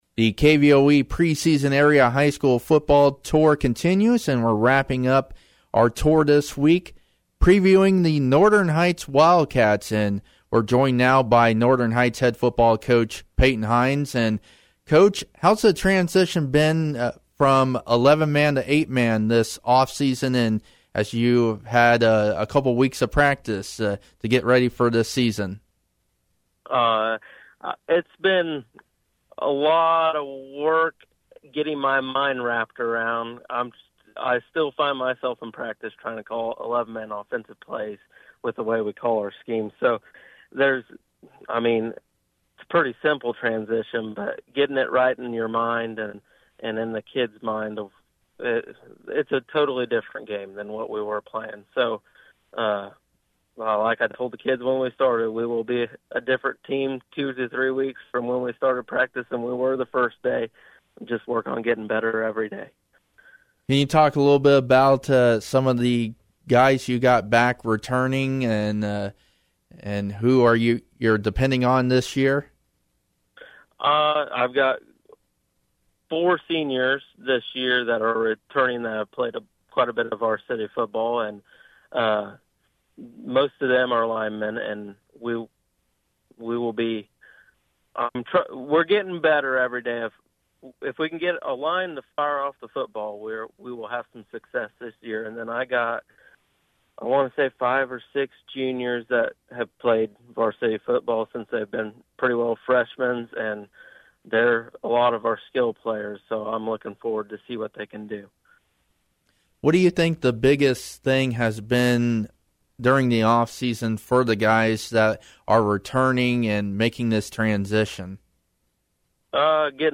Northern Heights Preseason Tour Stop featuring an interview